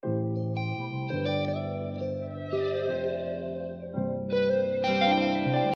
ПРиветствую, подскажите как называется этот гитарный звук \ прием в отрывках, звучит как теремин, без атаки, будто реверсом.